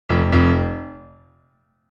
Two Deep Intense Piano Tones Sound Effect
Description: Two deep, intense piano tones perfect for adding drama, suspense, or emotion to videos, films, games, and multimedia projects.
Genres: Sound Effects
Two-deep-intense-piano-tones-sound-effect.mp3